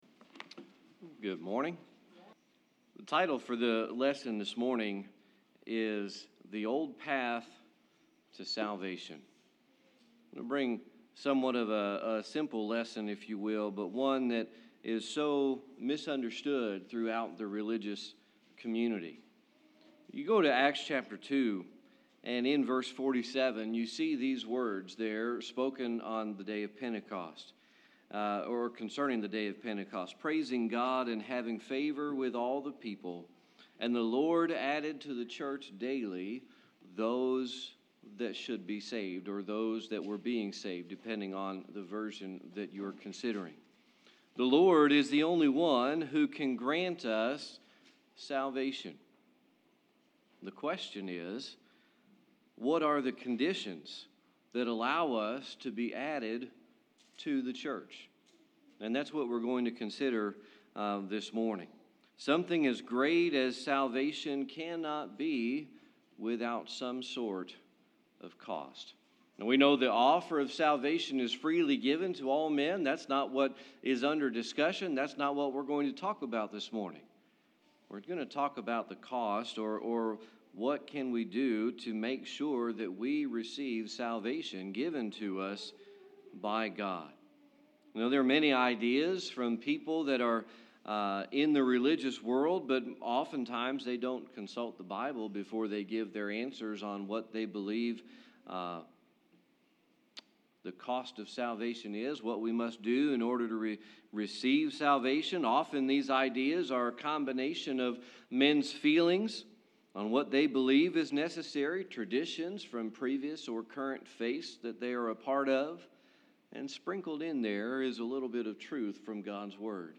Series: Sermon Archives
Jeremiah 6:16 Service Type: Sunday Morning Worship The title for the lesson this morning is The Old Path to Salvation .